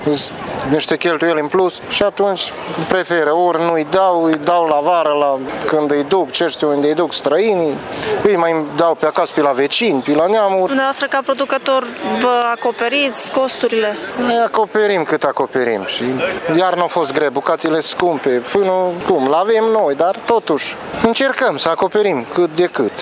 Pe de altă parte, ciobanii sunt supărați pe nemulțumirea cumpărătorilor și spun că prețurile abia le acoperă cheltuielile: